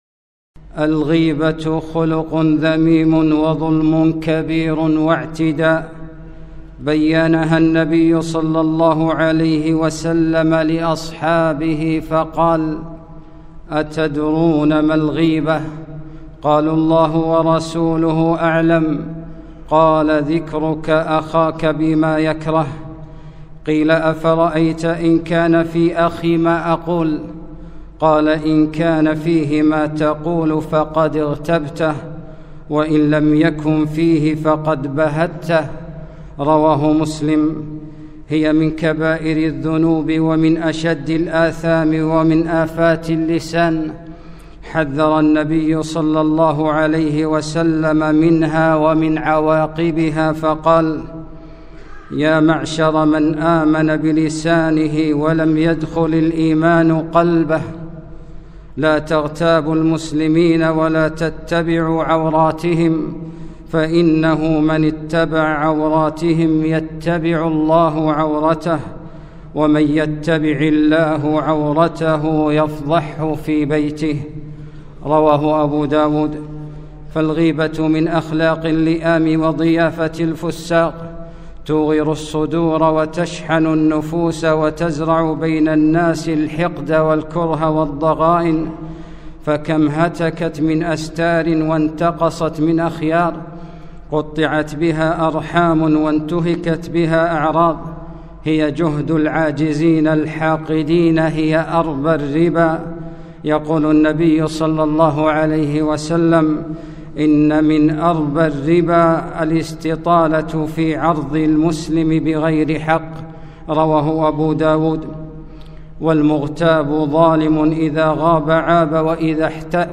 خطبة - أهلُ الغيبة والبهتان